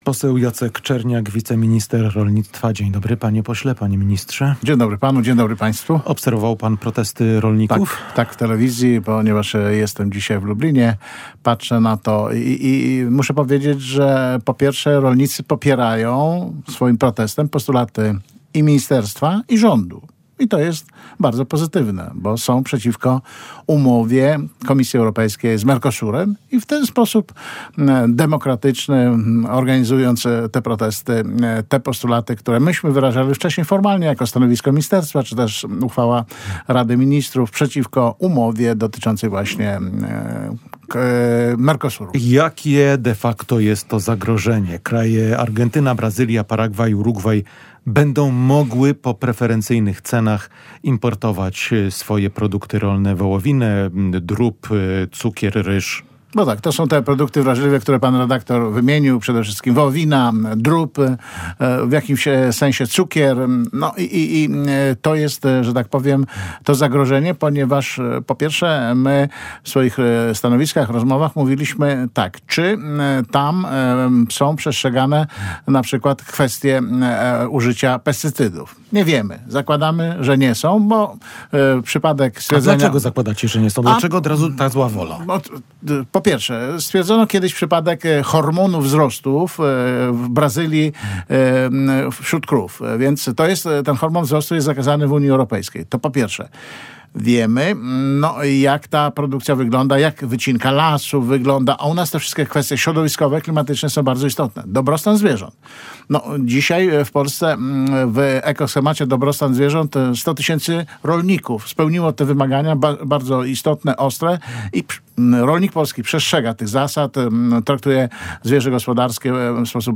– Rolnicy popierają swoim protestem postulaty Ministerstwa Rolnictwa i całego rządu. To bardzo pozytywne – mówił w Radiu Lublin wiceminister rolnictwa i rozwoju wsi Jacek Czerniak. Dziś (30.12) w całym kraju, również w województwie lubelskim, zorganizowano protesty rolników przeciwko umowie handlowej między Unią Europejską a państwami bloku Mercosur.